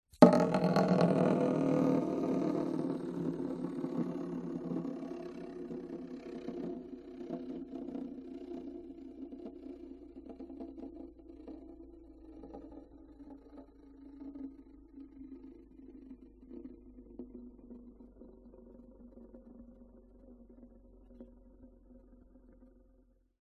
Звуки волчка
долго крутится